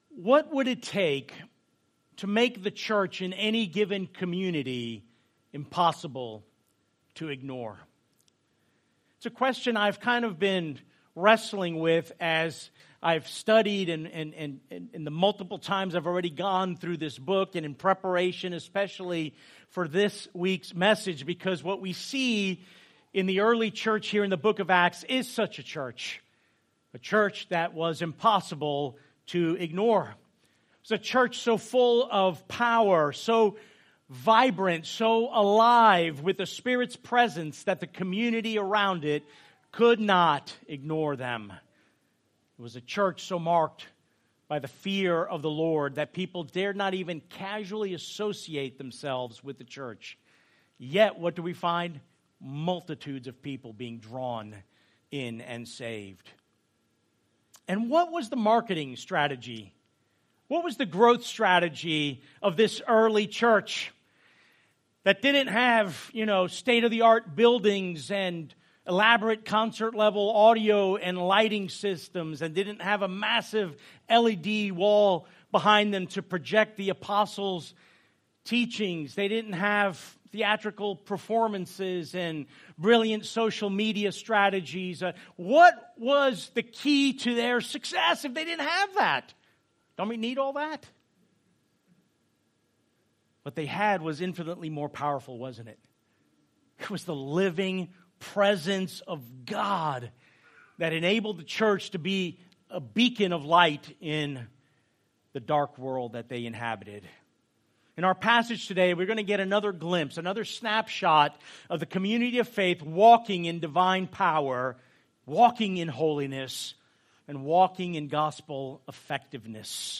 A message from the series "Psalms." Psalm 51:1-19